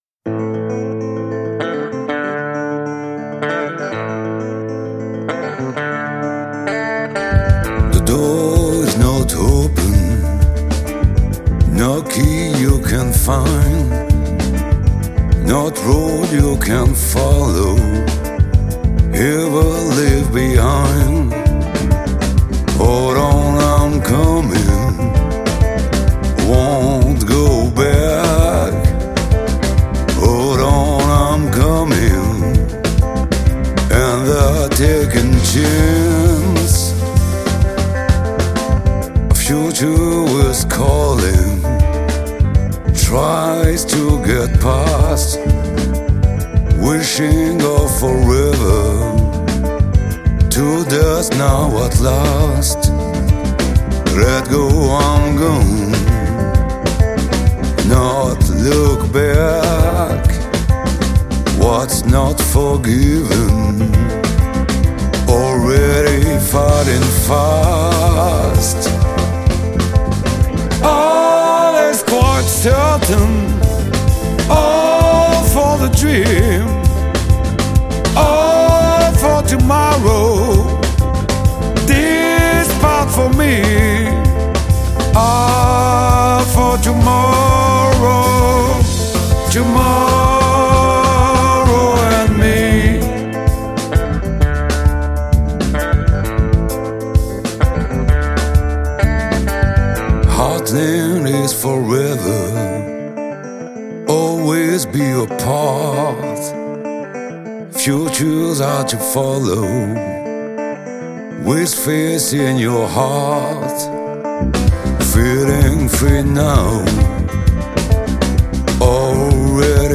LA PROFONDEUR DU BLUES MODERNE ET VOYAGEUR